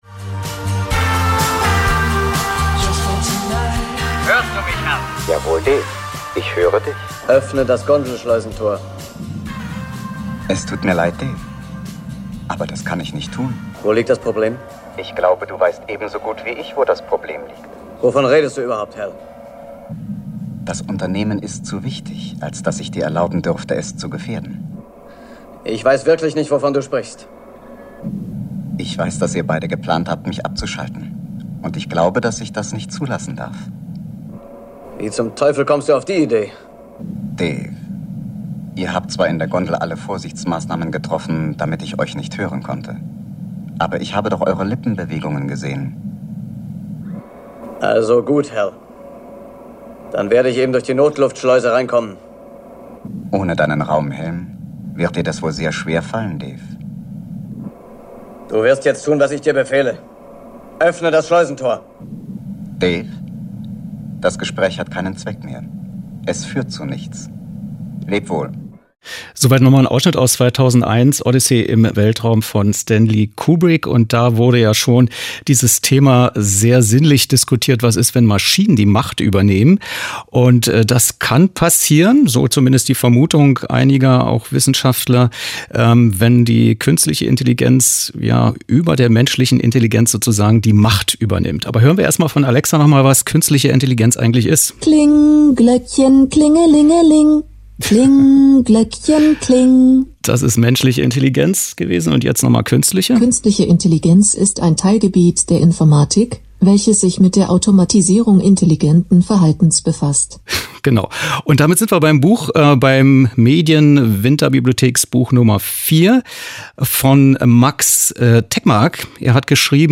Was: Studiogespräch zum Buch